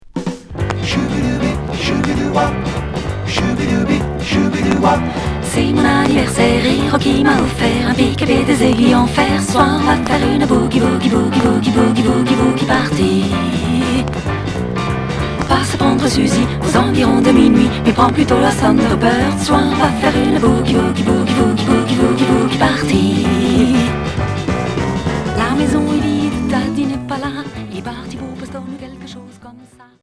Rock boogie